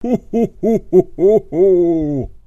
NUEVOS TONOS NAVIDEÑOS El saludo típico de PAPA NOEL a los niños Ho Ho HO !
Tono de navidad TONO MOVIL de HO HO HO
ho-ho-ho.mp3